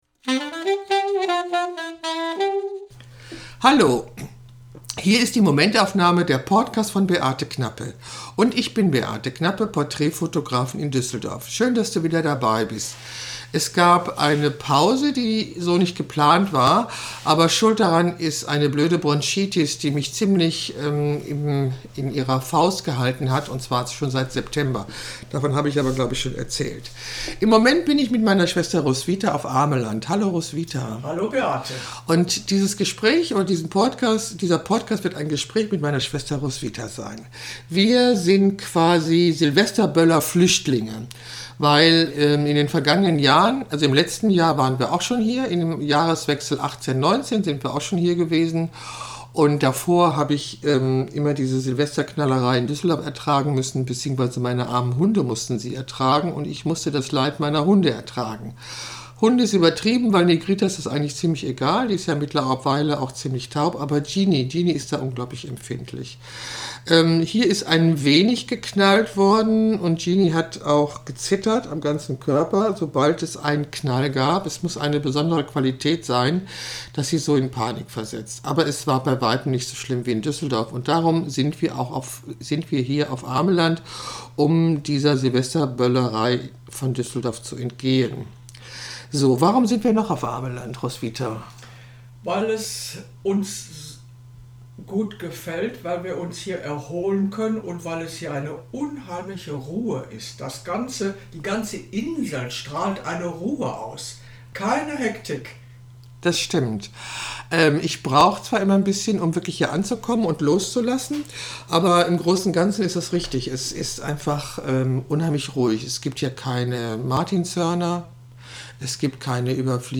Dies ist ein launiges Gespräch